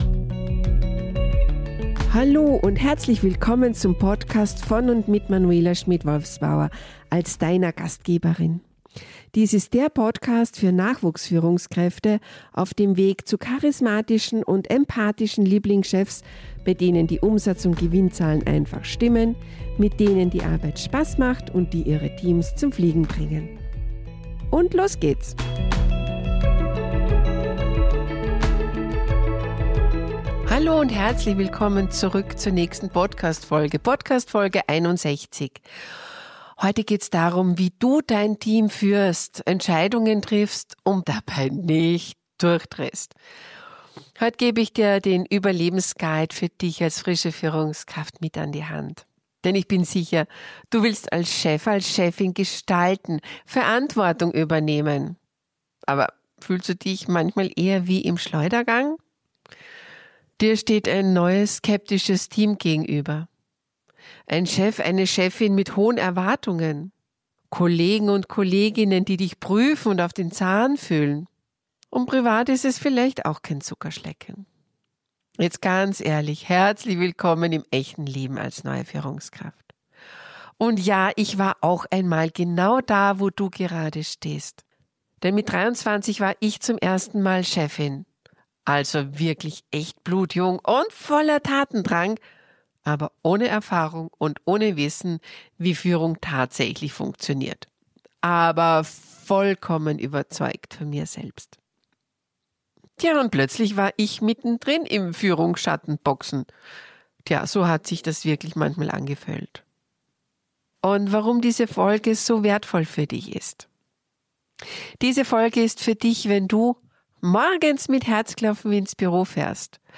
In dieser persönlichen Solo-Folge